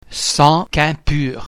(in the Marseillaise) the [g] is sounded [k]